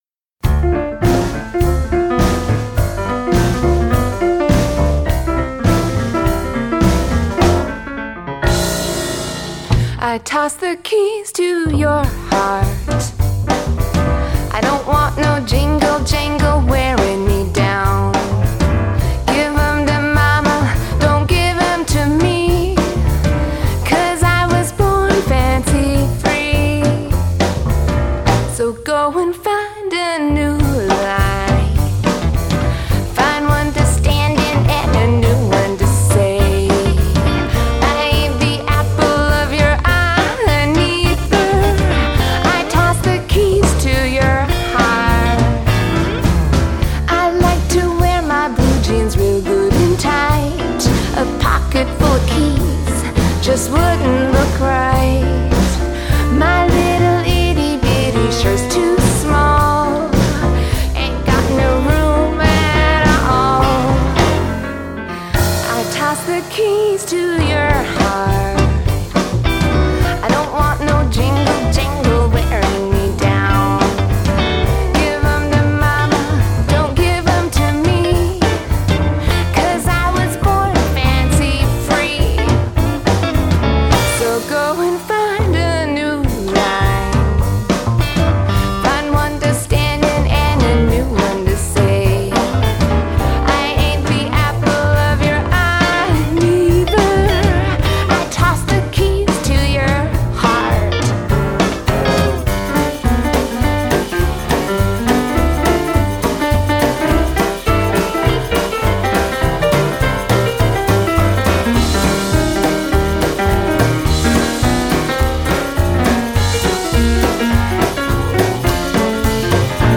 Adult Contemporary , Comedy , Indie Pop , Musical Theatre